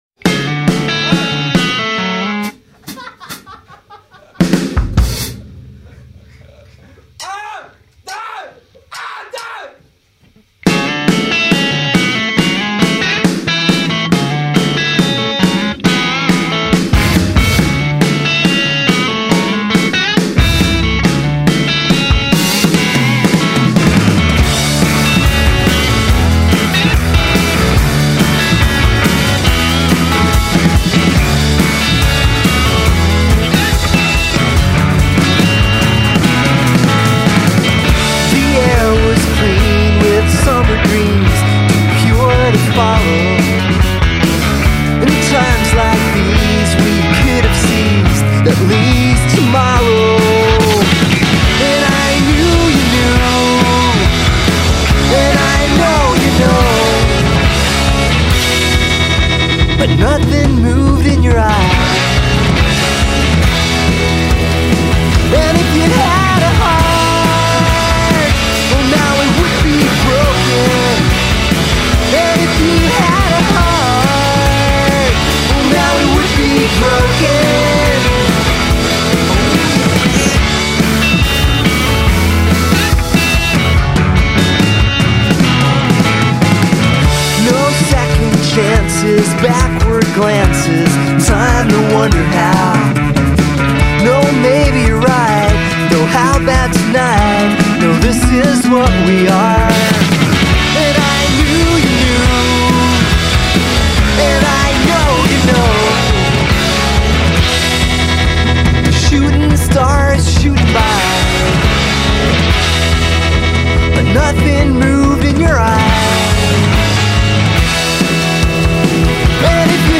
Indie.